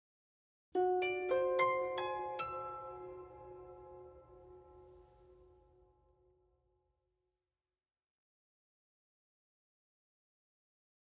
Harp, Arpeggio Reminder, Type 2